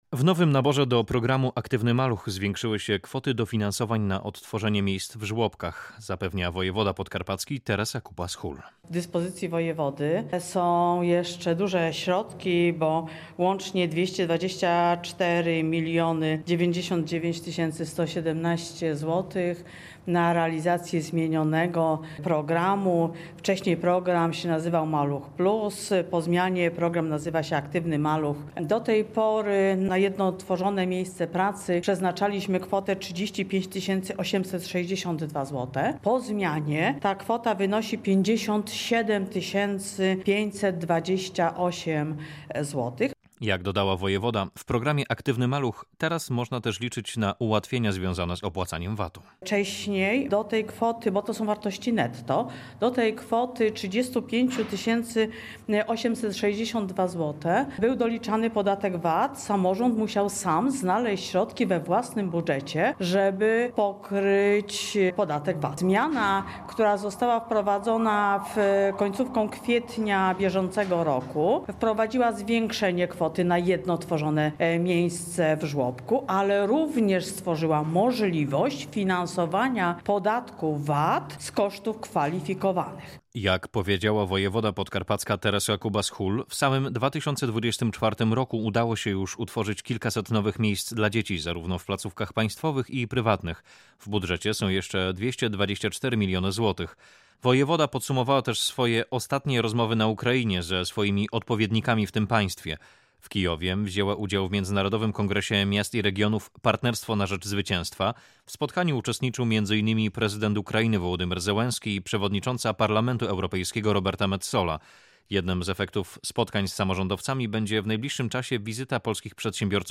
– powiedziała Teresa Kubas-Hul, wojewoda podkarpacki.
wojewoda-konferencjaed.mp3